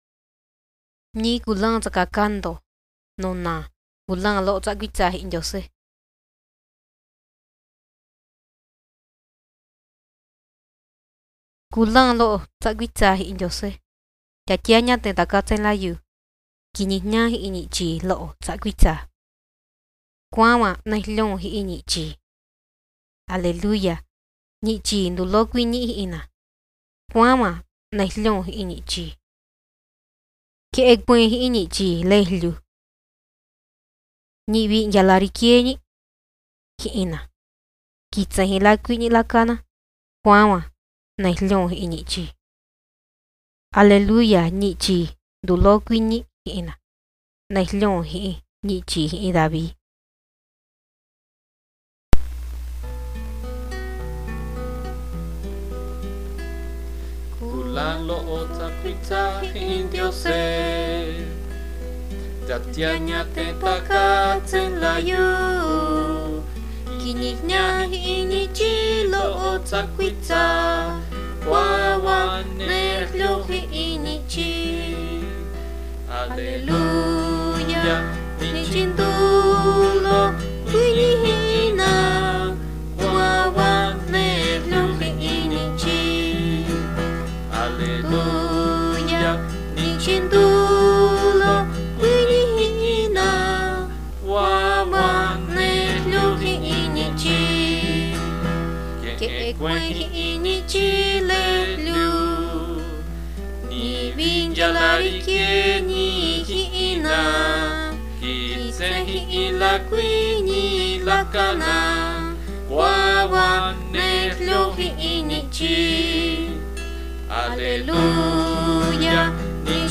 Coros en Chatino | Chatino de Zenzontepec